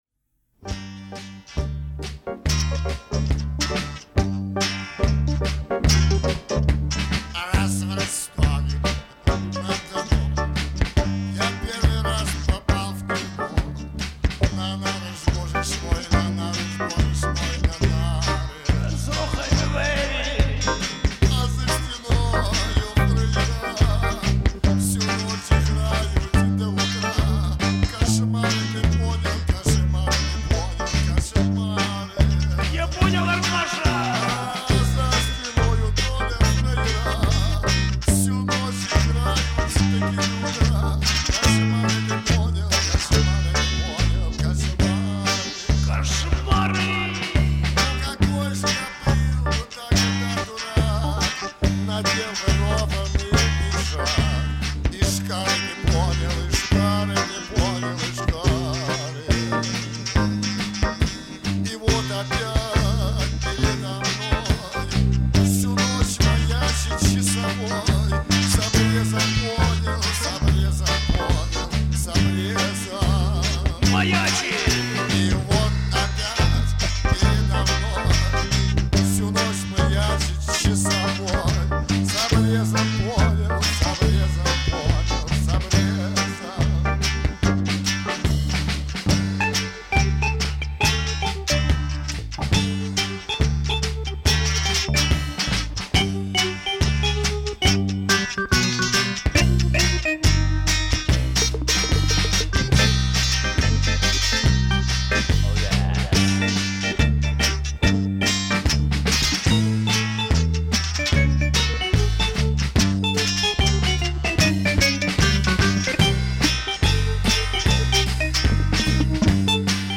Но ни разу не слышала ее целиком и в исполнении милой девы.